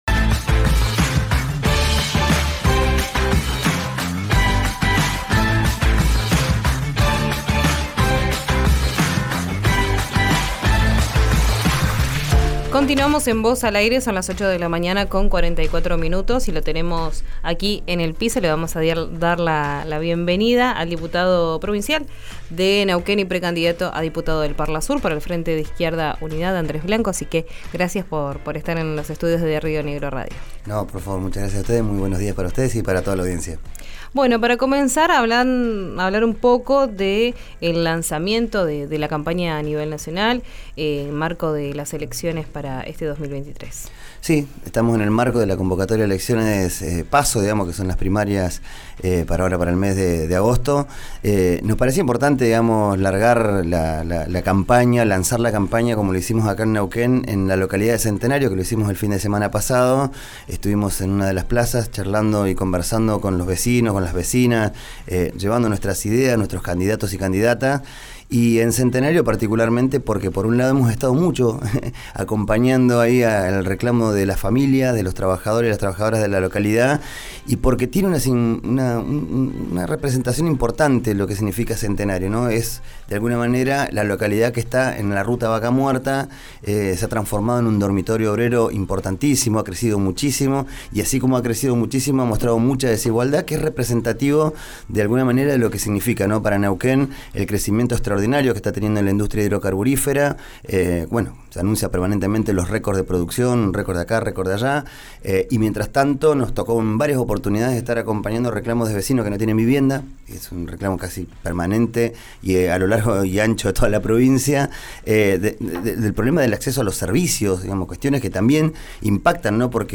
Escuchá la entrevista completa en RÍO NEGRO RADIO.
Andrés Blanco, diputado provincial de Neuquén y precandidato a diputado del Parlasur, visitó el estudio de RÍO NEGRO RADIO para contar las propuestas del espacio.